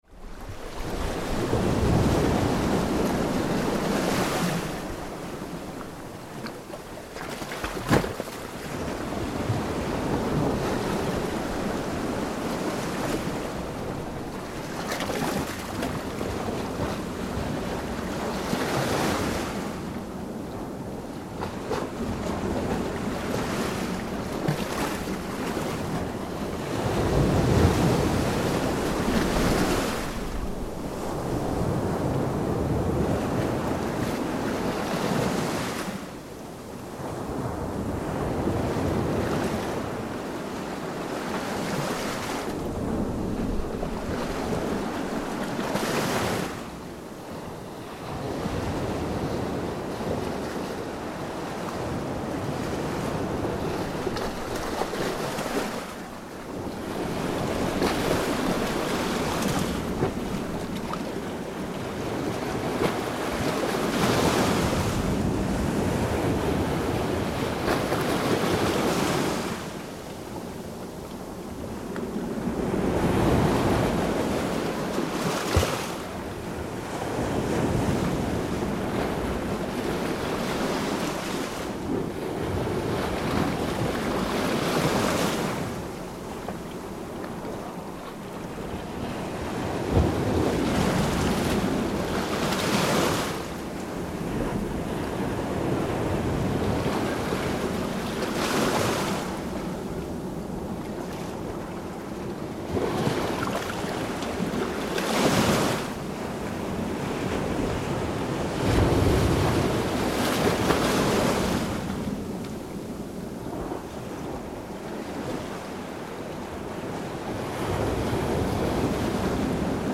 The result of this dedication is a beautiful, clean beach with crystalline waters in a beautiful setting. Here, waves break under a rocky inlet on this most perfect of Mediterranean beaches.